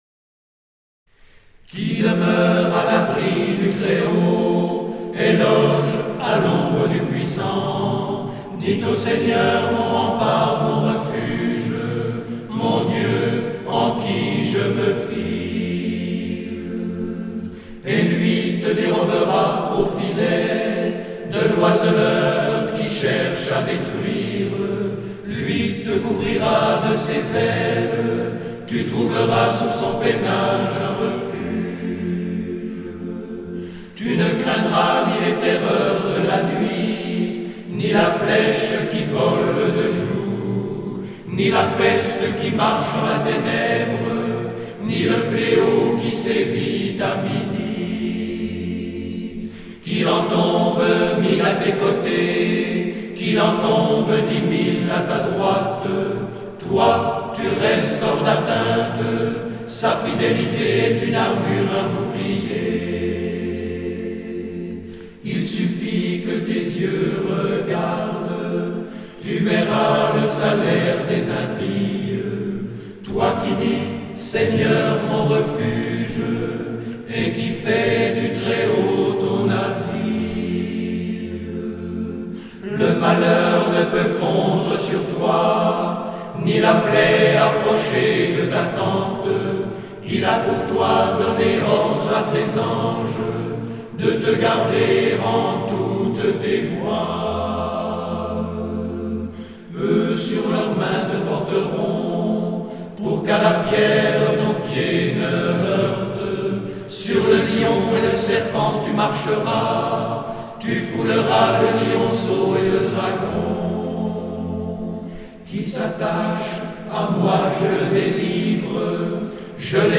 “Le Psaume 91 chanté par les pèlerins de Montbéliard“.